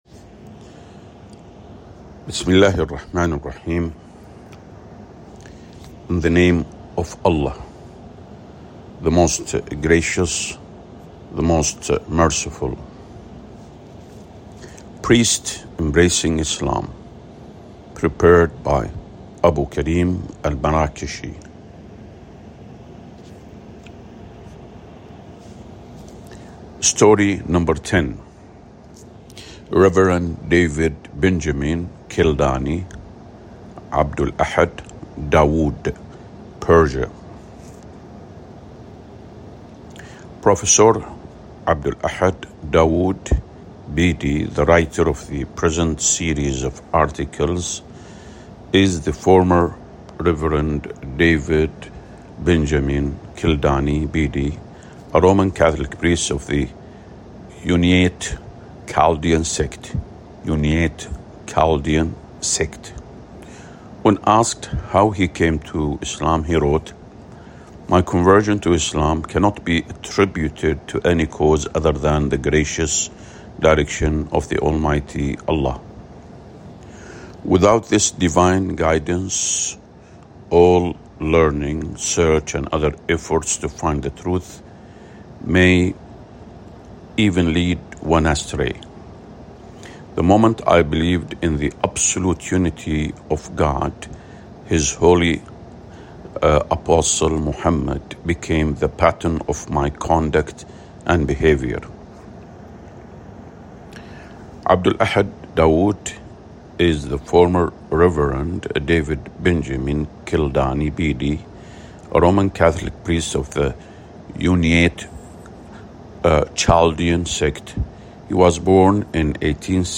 Audiobook
priests-embracing-islam_audiobook_english_10.mp3